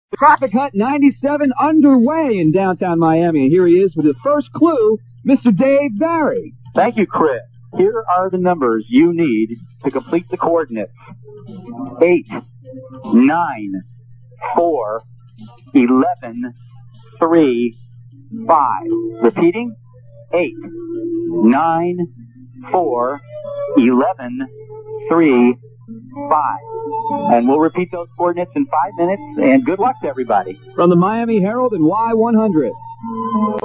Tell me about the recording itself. AUDIO - The Opening Clue (As broadcast on the radio)